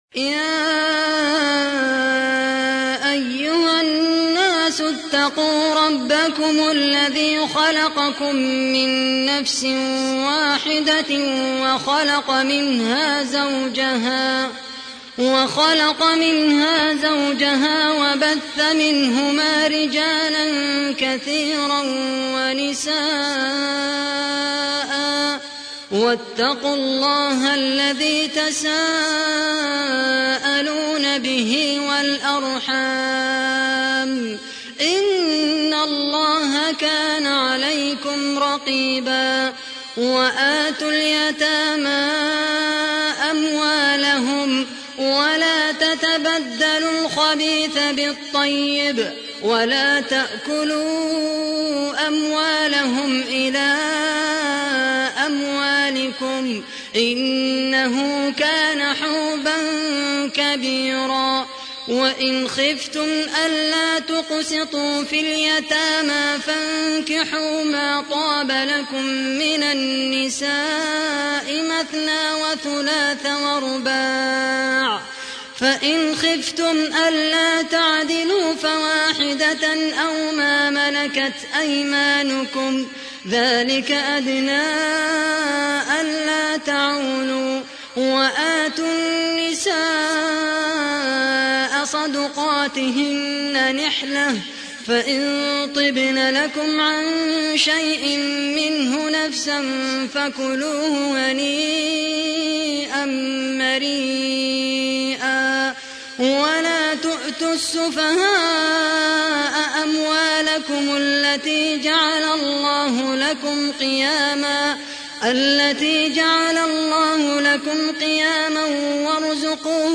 تحميل : 4. سورة النساء / القارئ خالد القحطاني / القرآن الكريم / موقع يا حسين